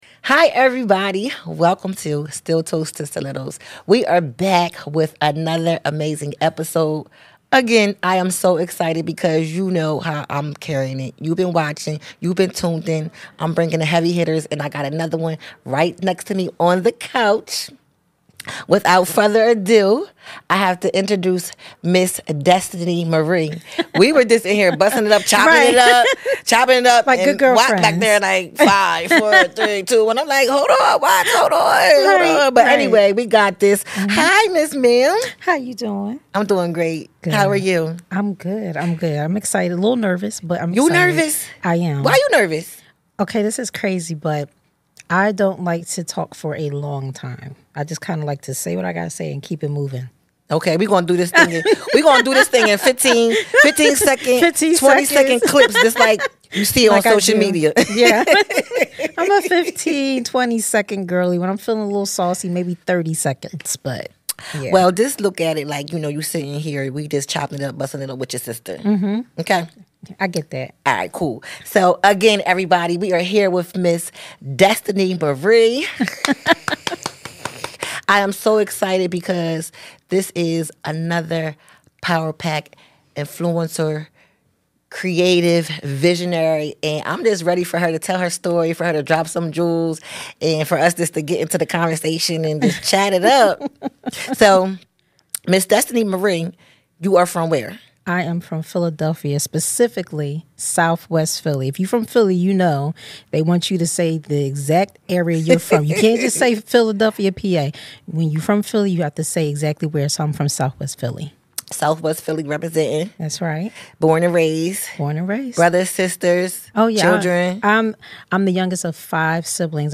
a powerful conversation on identity, resilience, and self-expression.